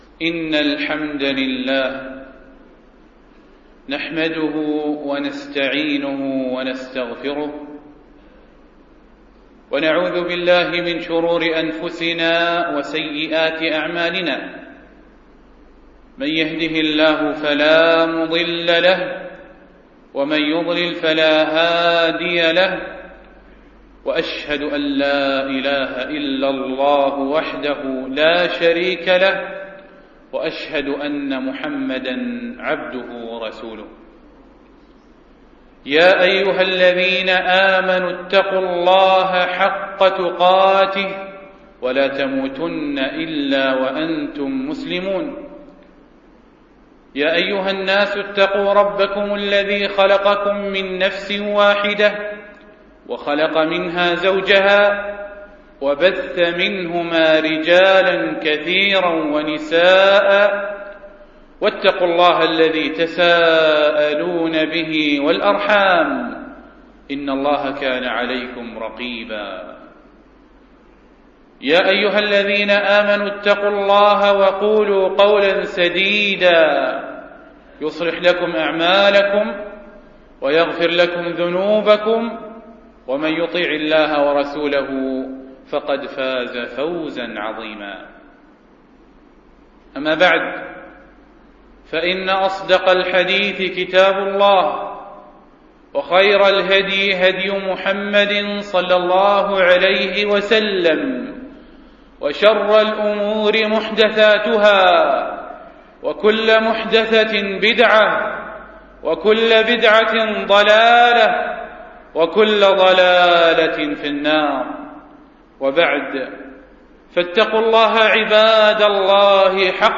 خطب - Page 2 of 923 - موقع دروس الإمارات